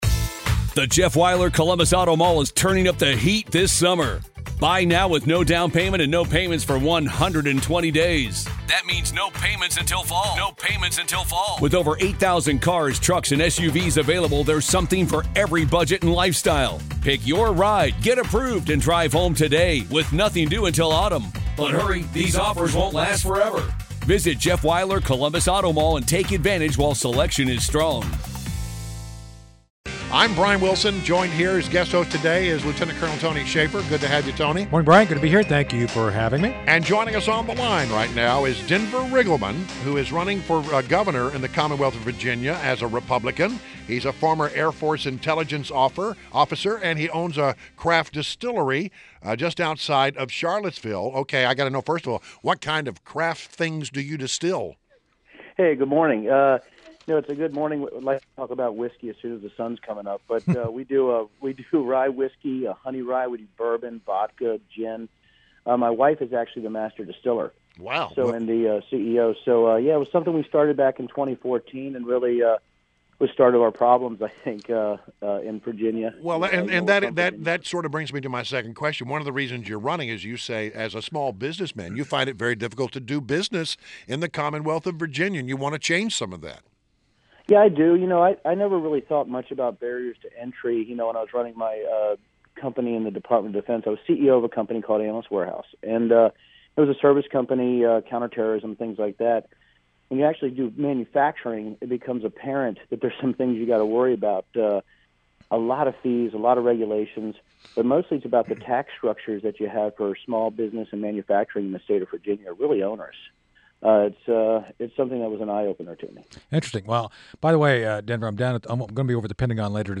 WMAL Interview - DENVER RIGGLEMAN - 01.25.17